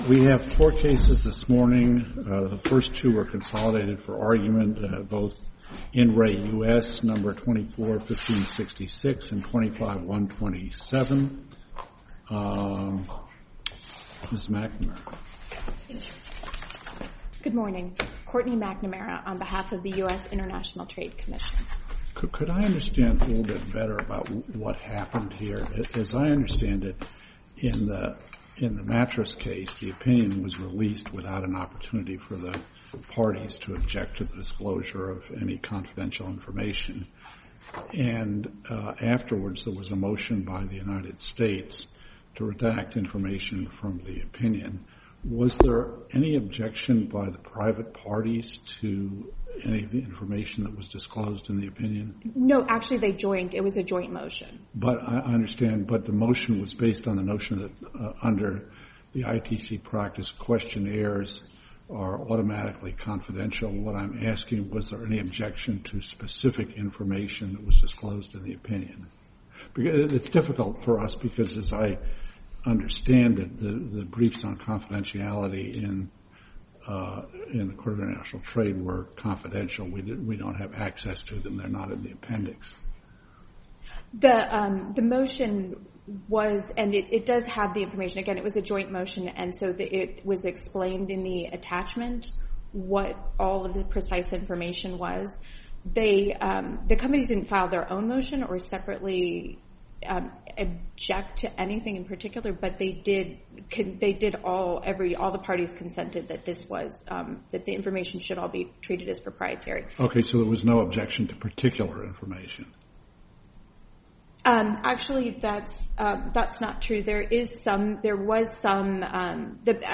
In-re-United-States-Oral-Argument-1.mp3